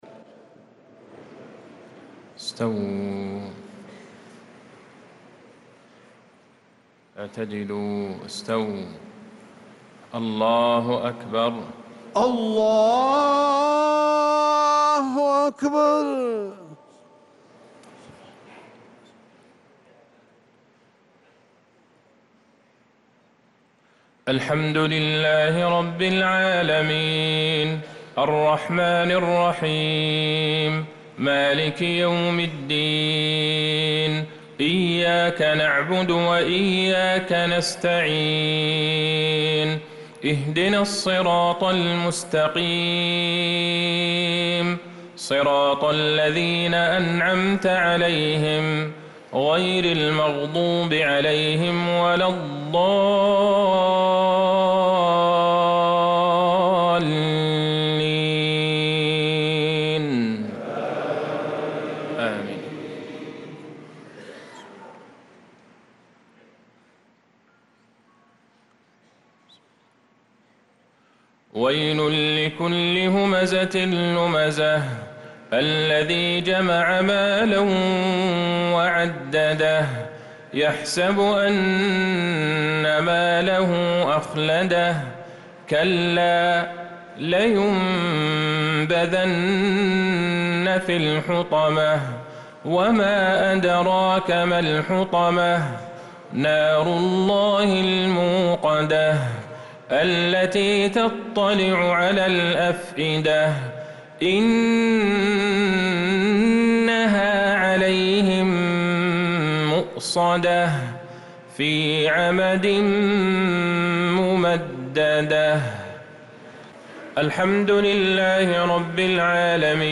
تِلَاوَات الْحَرَمَيْن .